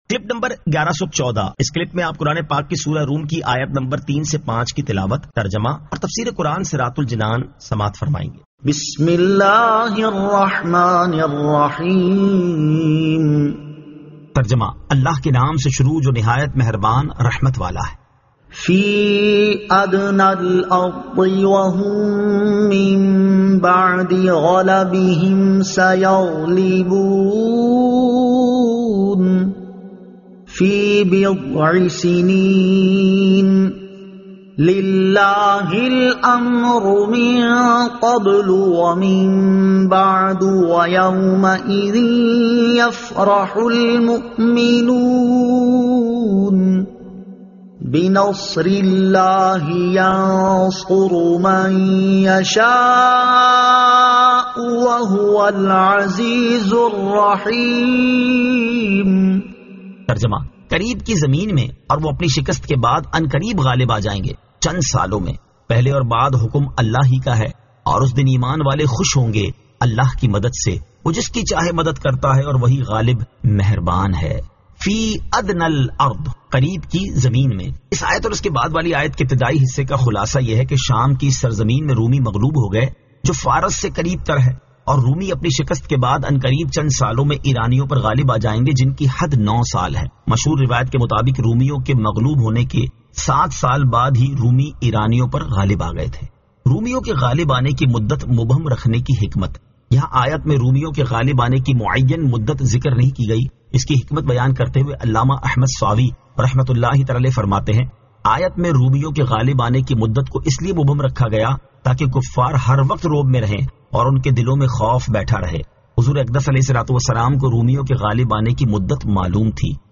Surah Ar-Rum 03 To 05 Tilawat , Tarjama , Tafseer